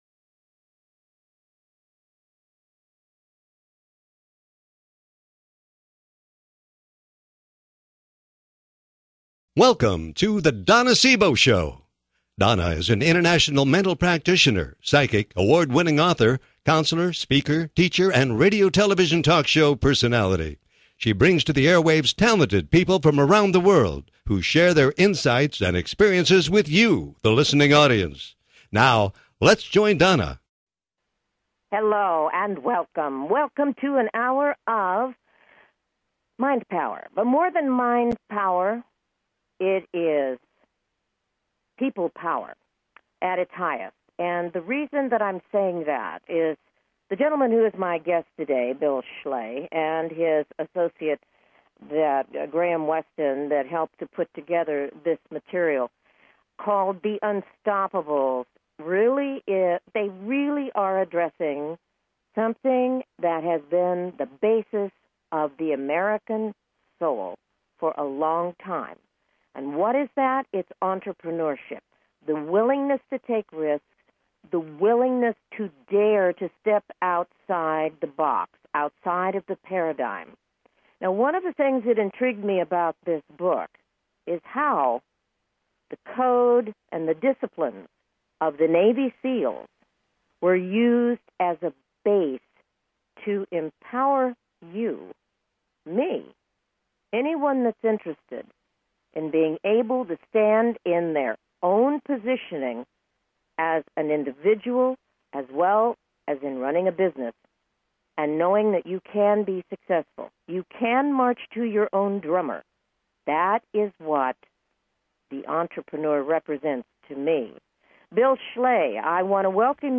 Her interviews embody a golden voice that shines with passion, purpose, sincerity and humor.
Talk Show
Callers are welcome to call in for a live on air psychic reading during the second half hour of each show.